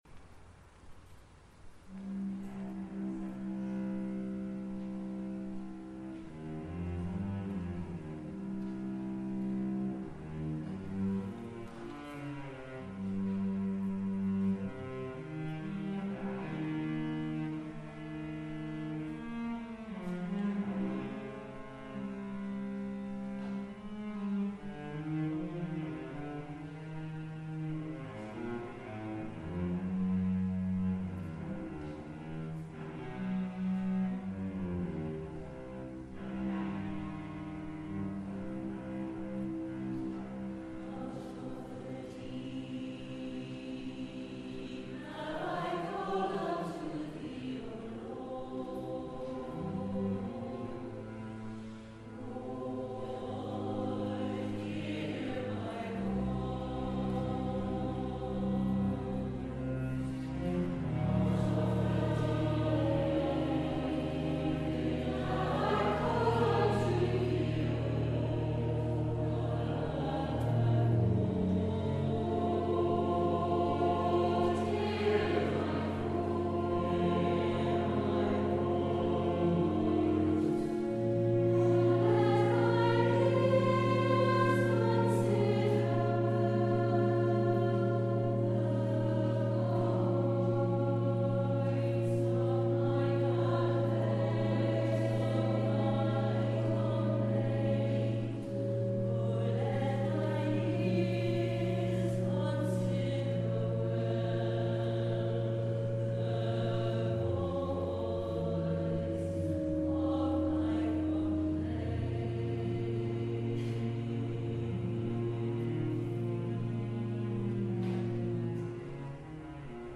From the Requiem by John Rutter, performed at the Simon Balle Choral Concert 2017